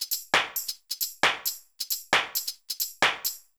GAR Beat - Mix 4.wav